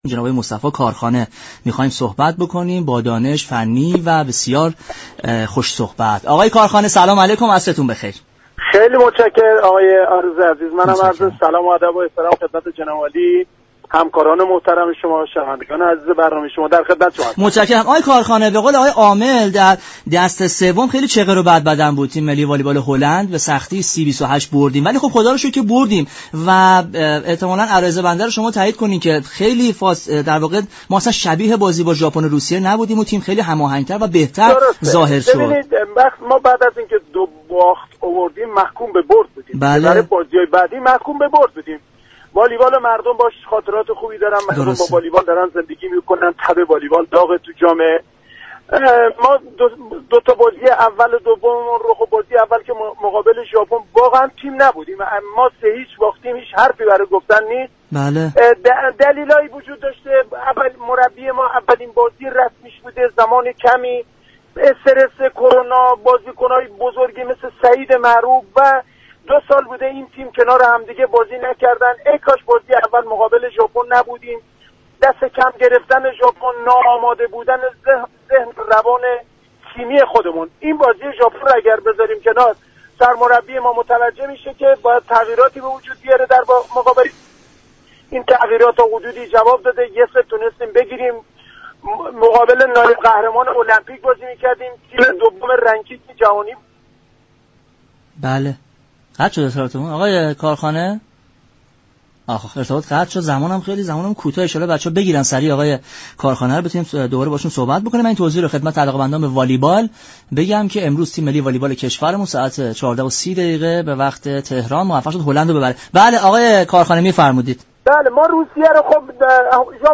برنامه ورزش ایران شنبه تا چهارشنبه هر هفته ساعت 18:30 از رادیو ایران پخش می شود.